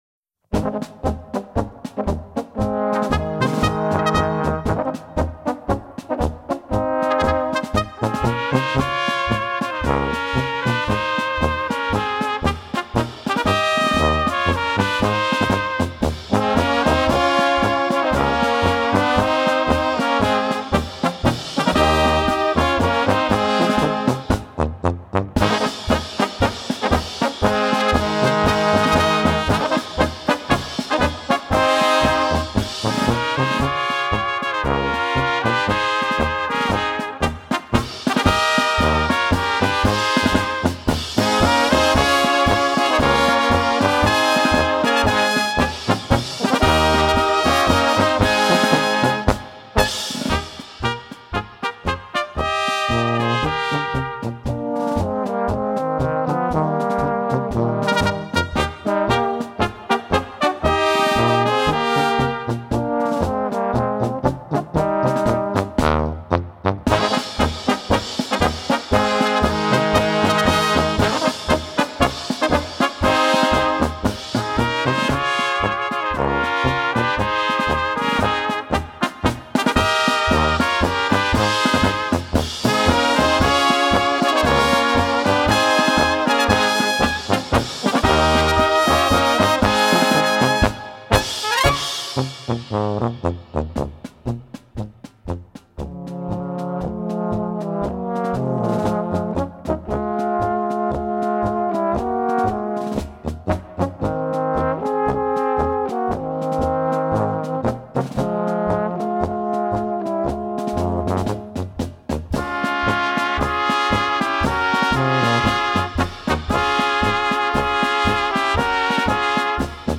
Gattung: Polka für Böhmische Besetzung
Besetzung: Kleine Blasmusik-Besetzung
1.Flügelhorn B
2.Flügelhorn B
Trompete B
Tenorhorn B
Bariton B/C
Tuba B/C
Schlagzeug